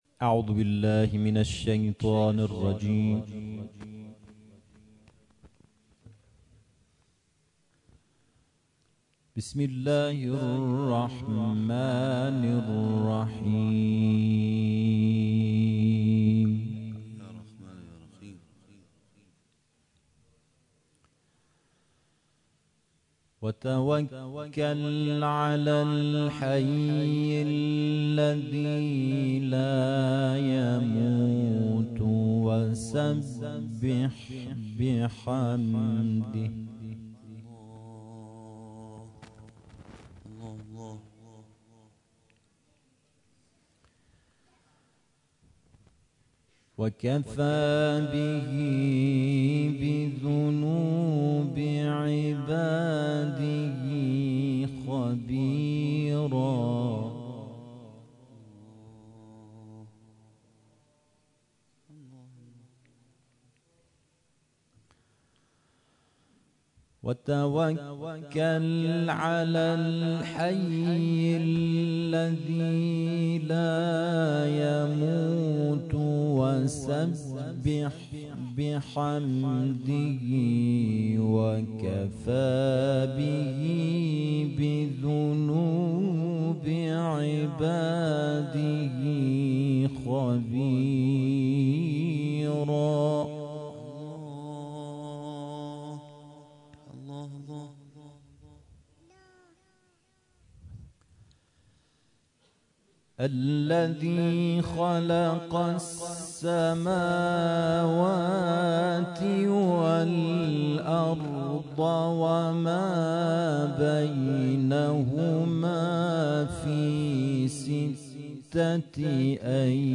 گروه جلسات و محافل ــ کرسیهای تلاوت نفحاتالقرآن